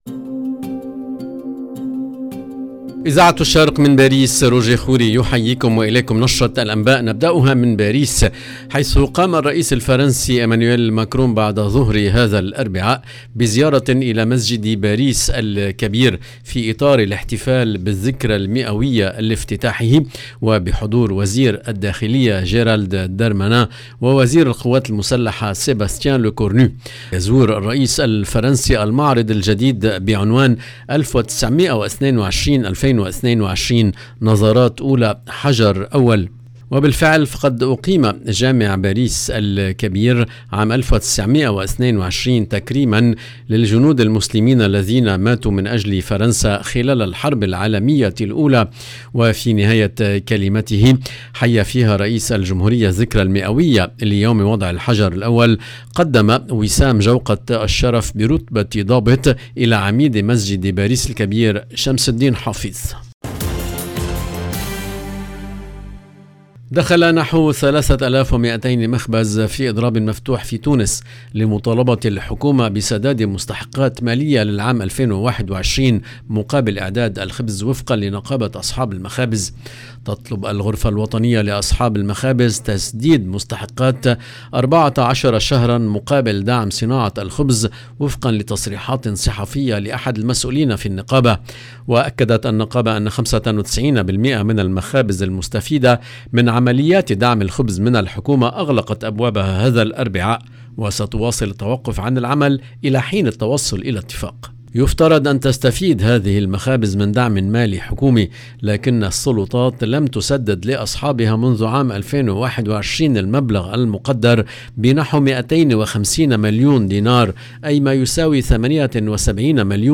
LE JOURNAL EN LANGUE ARABE DU SOIR DU 19/10/22